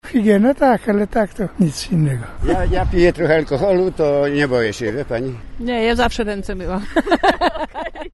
Sonda-koronawirus-2.mp3